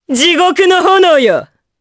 Morrah-Vox_Skill2_jp.wav